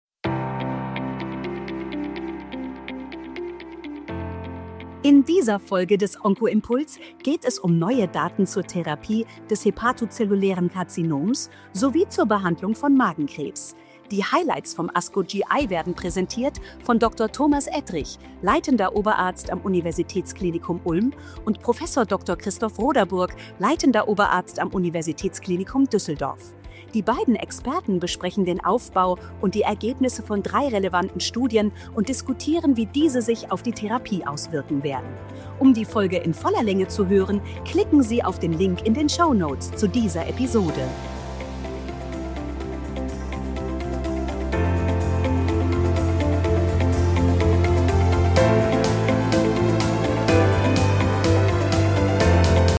Die Experten besprechen ihre Highlights vom ASCO-GI-Kongress 2026